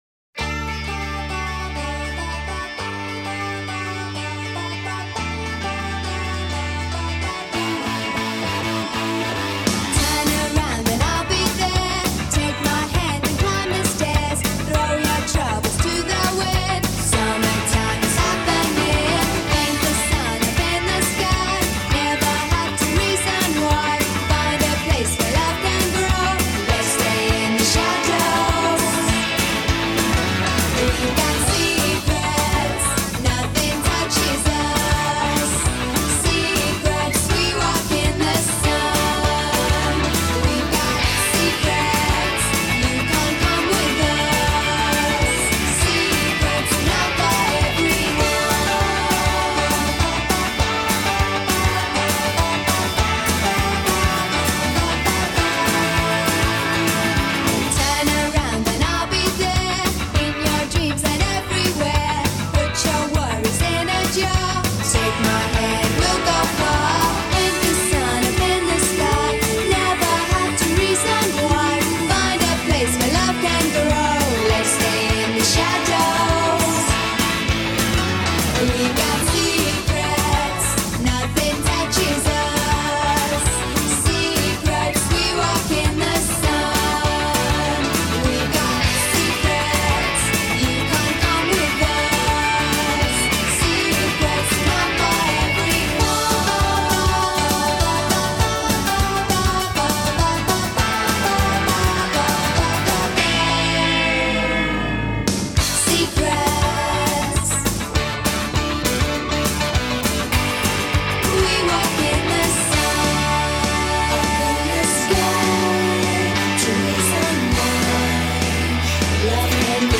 I’m a sucker for bands with bubbly female singers